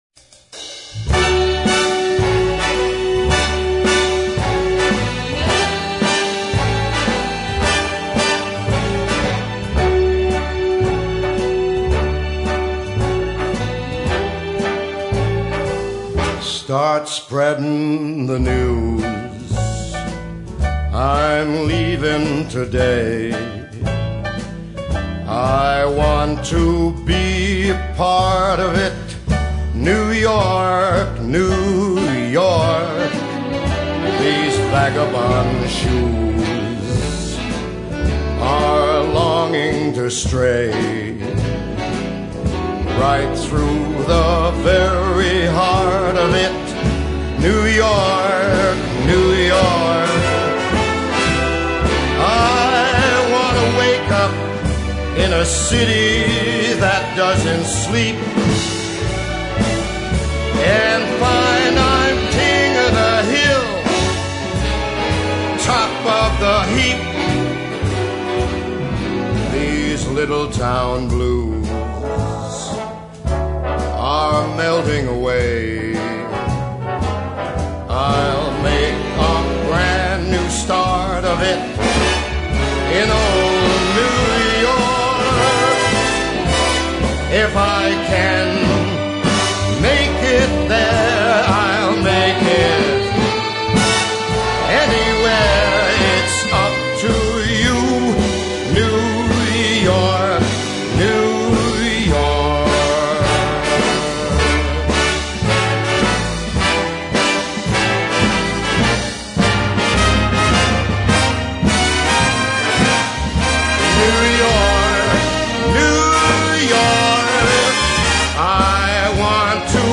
Boleros